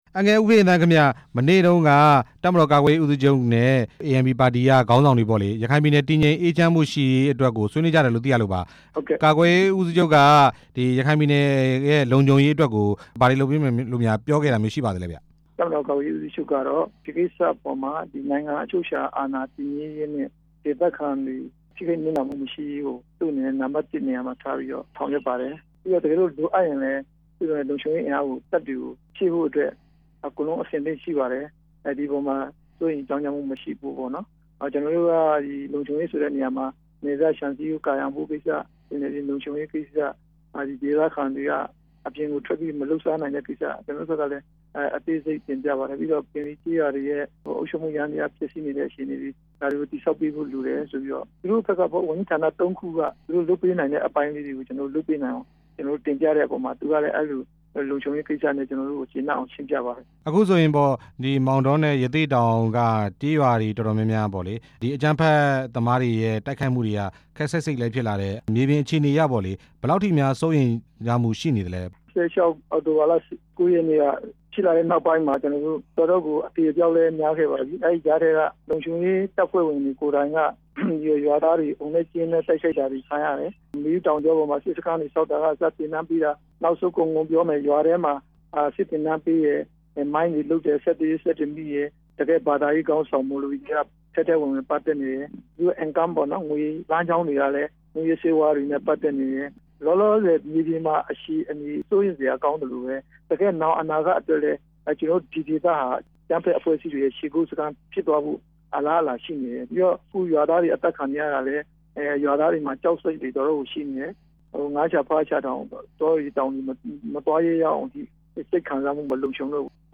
တပ်မတော်ကာကွယ်ရေးဦးစီးချုပ်နဲ့ ရခိုင်အမျိုးသားပါတီ တွေ့ဆုံမှုအကြောင်း မေးမြန်းချက်